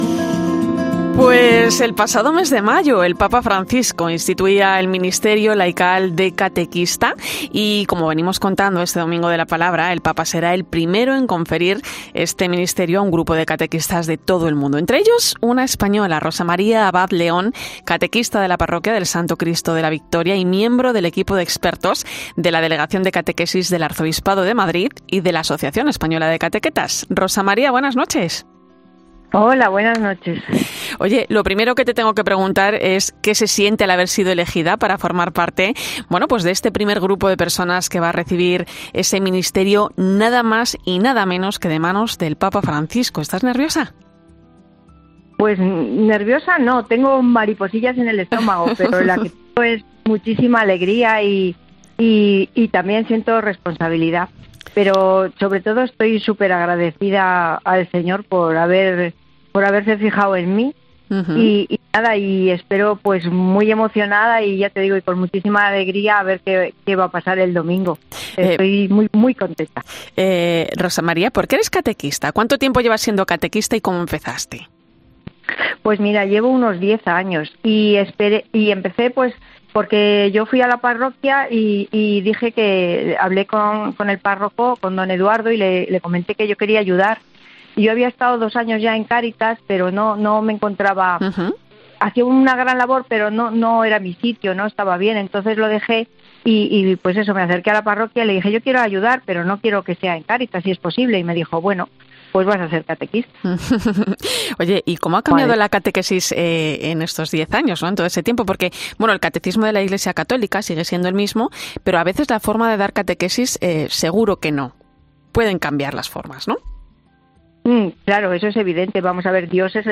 Hablamos con una catequista española que será parte del ministerio laical de catequista que instituyó el pasado mayo el papa Francisco